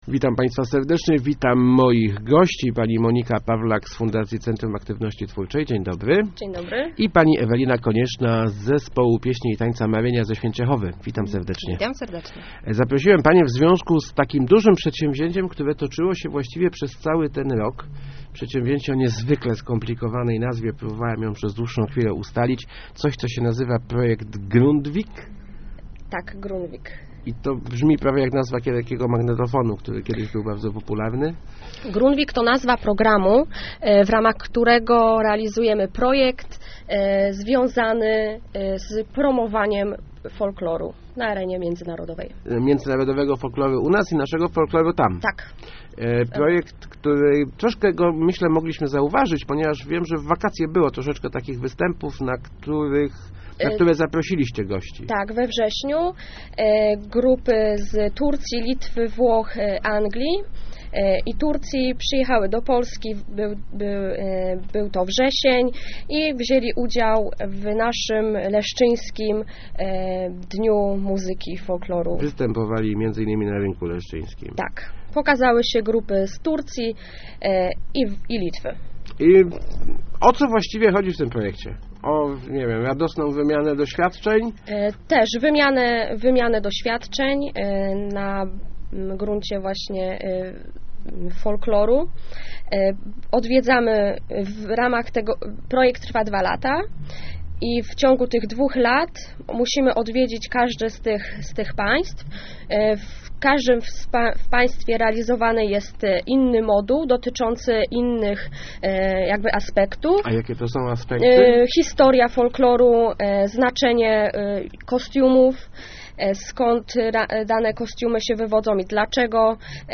Start arrow Rozmowy Elki arrow "Marynia" jedzie do Anglii